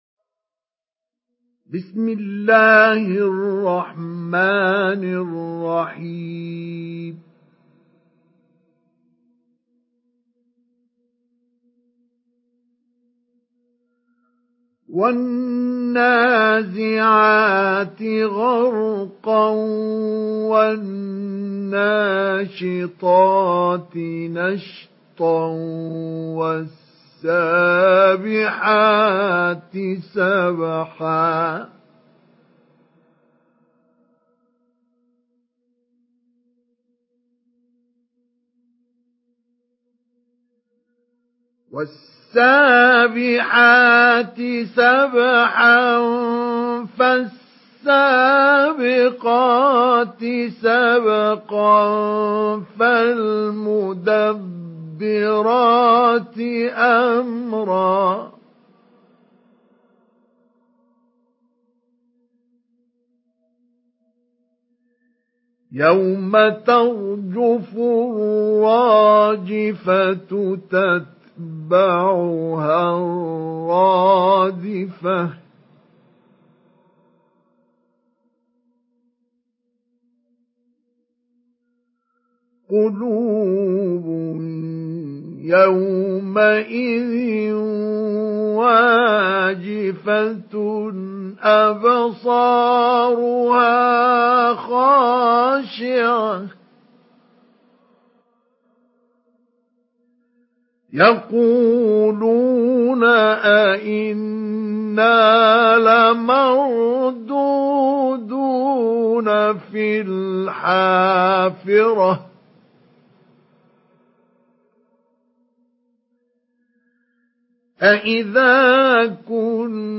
Surah An-Naziat MP3 in the Voice of Mustafa Ismail Mujawwad in Hafs Narration
Surah An-Naziat MP3 by Mustafa Ismail Mujawwad in Hafs An Asim narration.